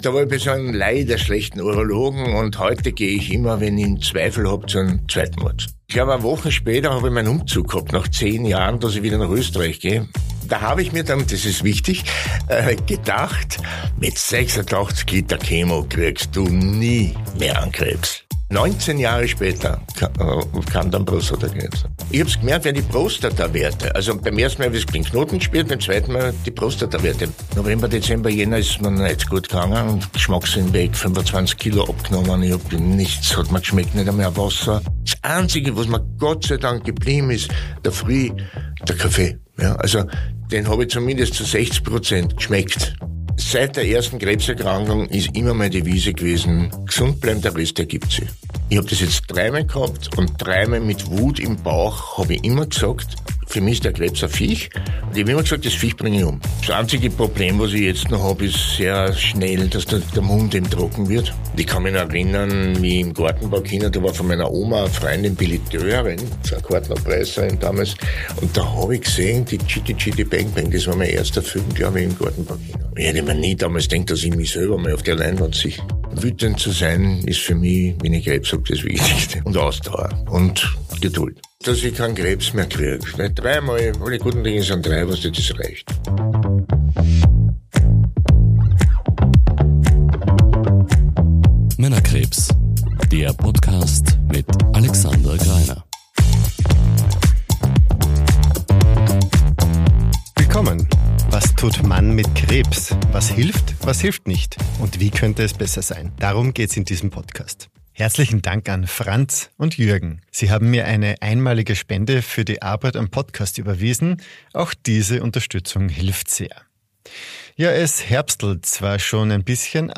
Gespräch) · Folge 24 ~ Männerkrebs – Was tut Mann mit Krebs?